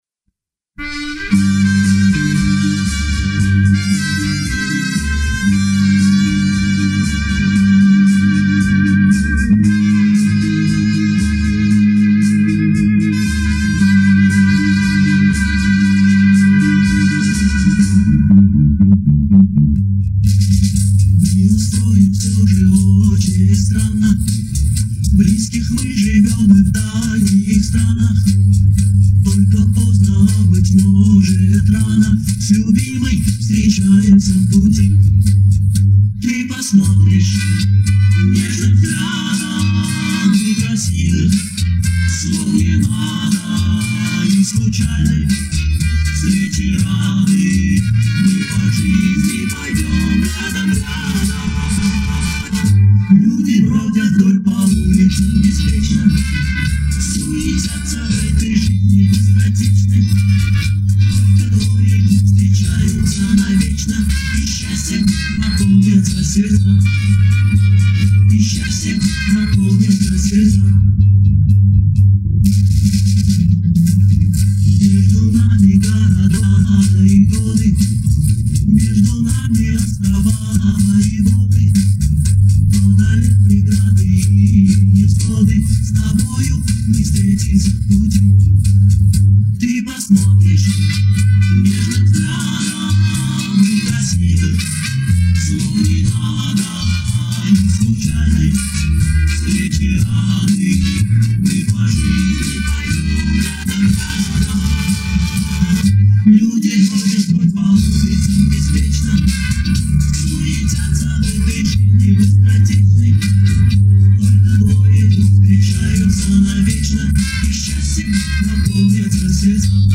Как обычно, запись ни к черту.